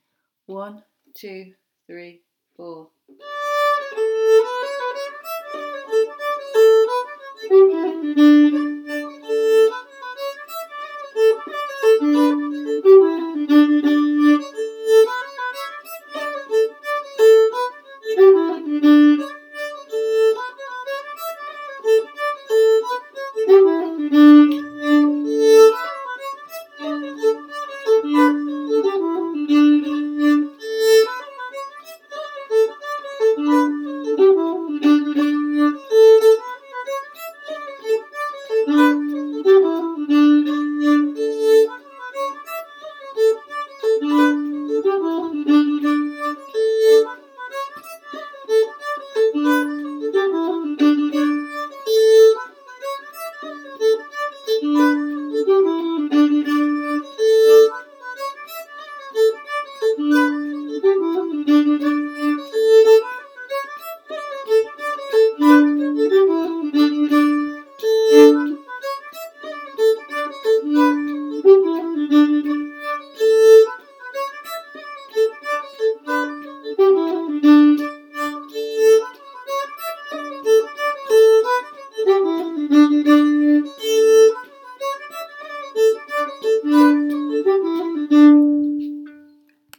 A Part Only